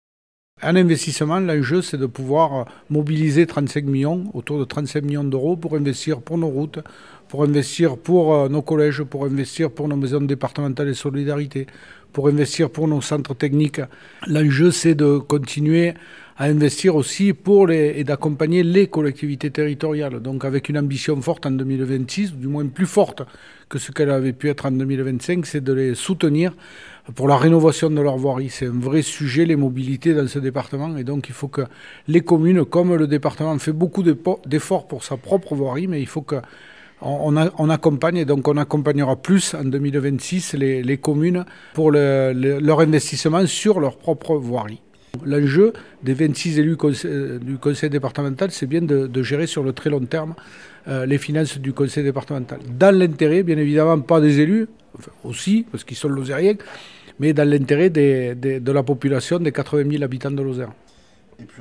Le président du Conseil départemental, Laurent Suau, détaille les enjeux de ce budget 2026.